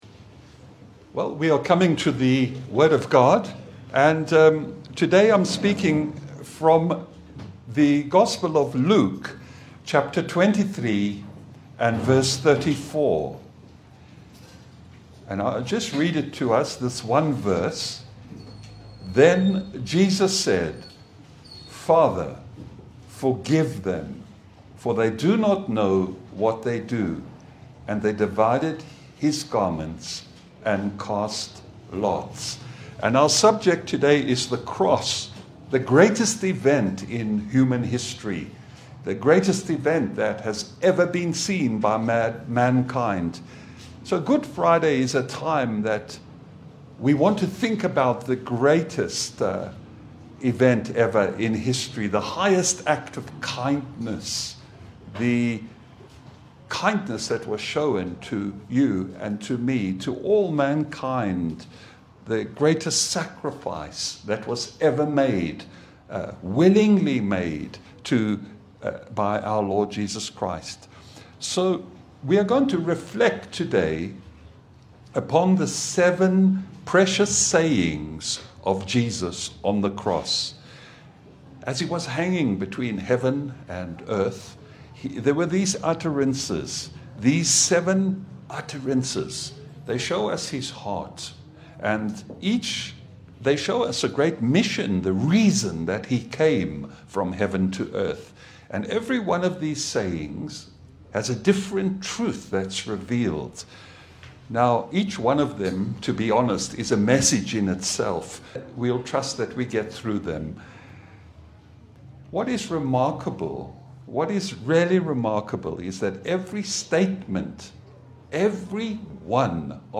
Service Type: Sunday Bible fellowship